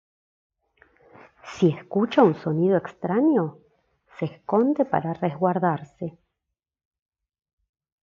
AUDIOCUENTO BASADO EN LA CANCION DE JUDITH AKOSCHKY